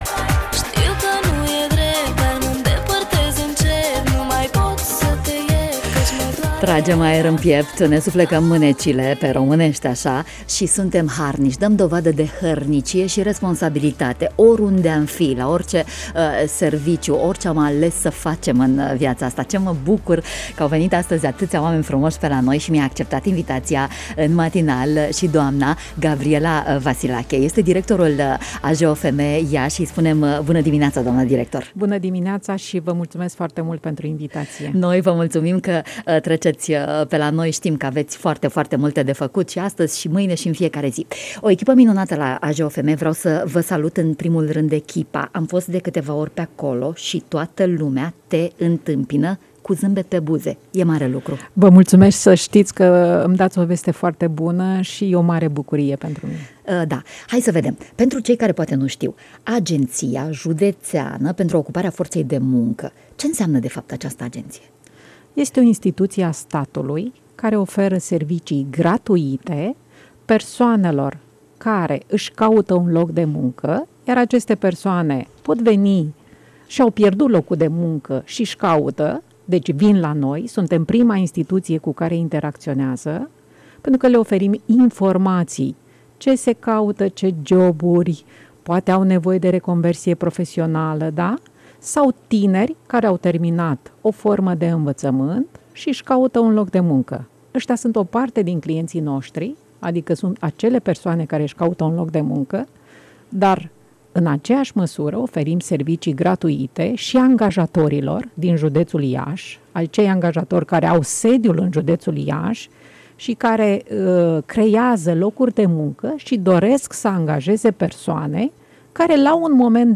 29-aug-19-BD-Intv-AJOFM.mp3